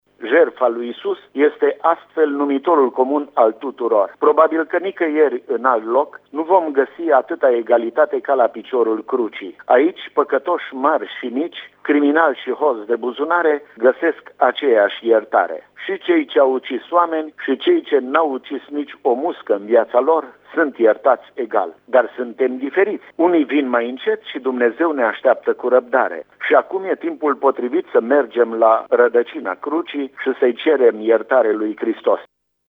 Despre semnificaţia acestei zile ne vorbeşte preotul ortodox din Tg Mureş